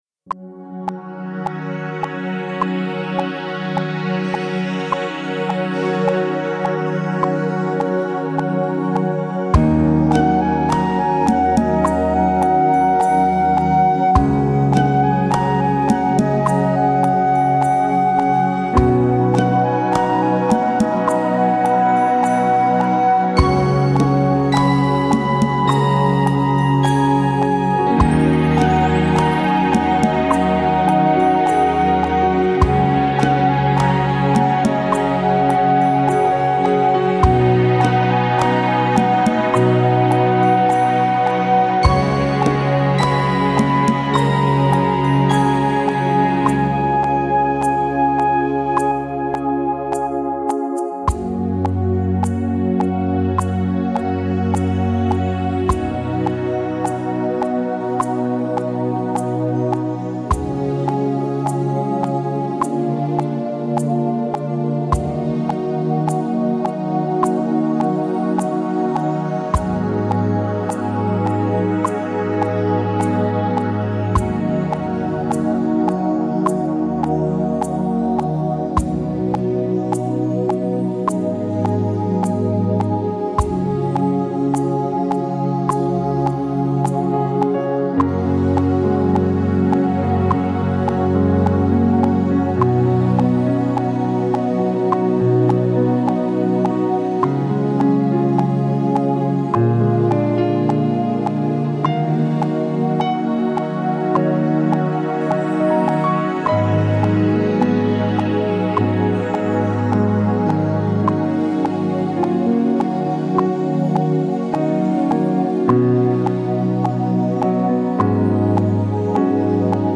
Nota: C# BPM: 102 Measure: 4/4
Click Guide Drums Bells Keys Strings Flute